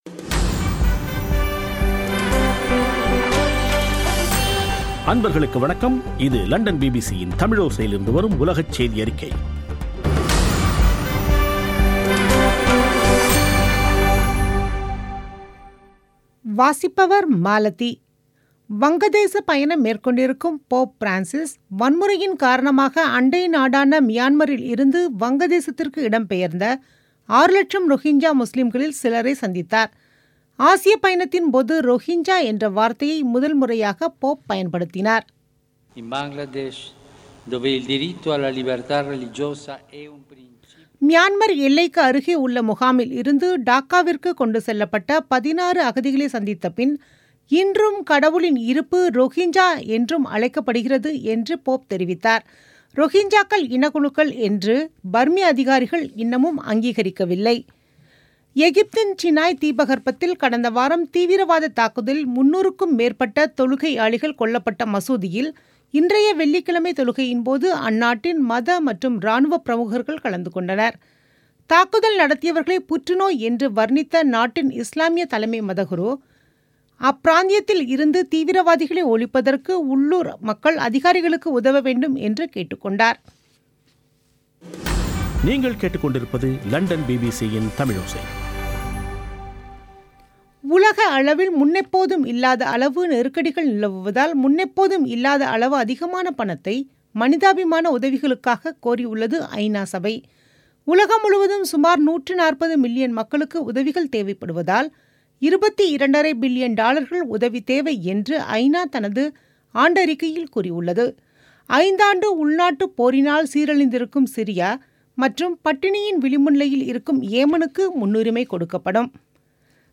பிபிசி தமிழோசை செய்தியறிக்கை (01/12/2017)